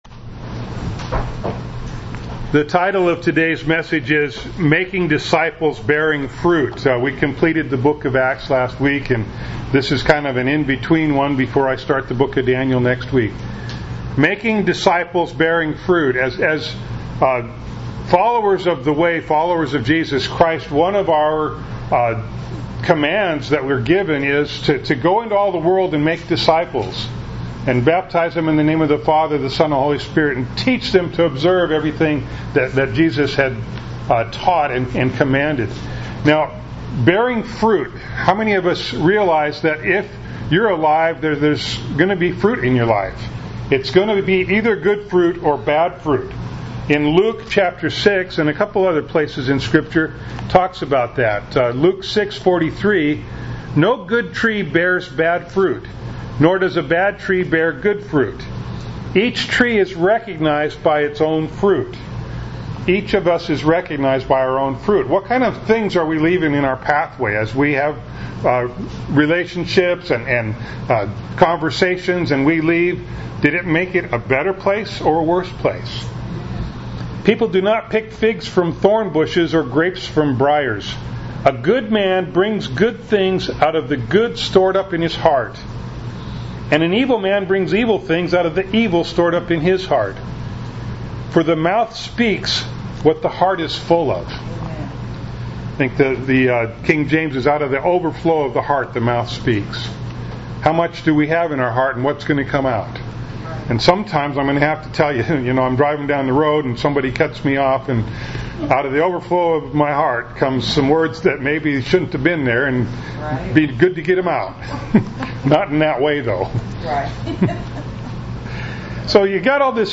Series: Village Missions Sunday